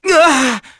Neraxis-Vox_Damage_kr_02.wav